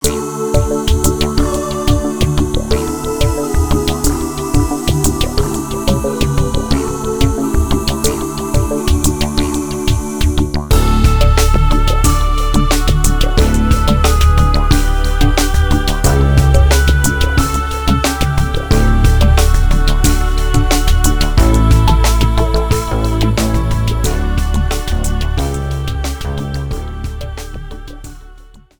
Classical music with a modern edge